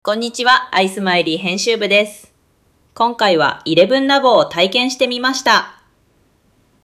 お聞きいただけましたか？こちらの音声は人間が話しているわけではなく、実際にElevenLabsで作成したAI音声です。
これほど自然で高品質な音声だと、調整が難しそうに思えますが、この音声はElevenLabsを使用することで1分程度で作成しました。